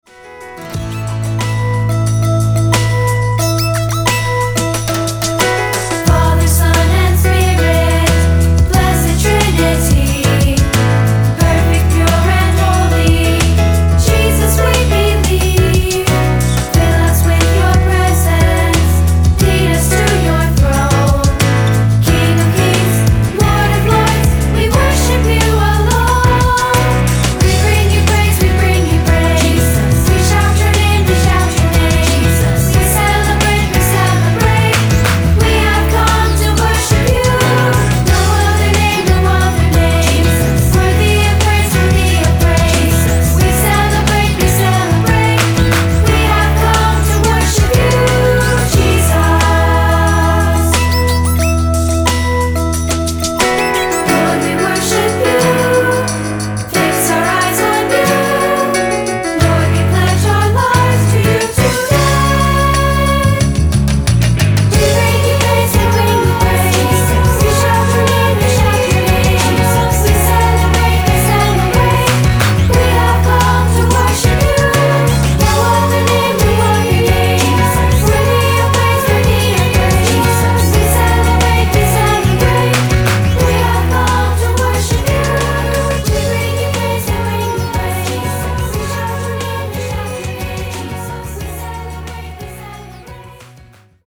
and games for children's choirs.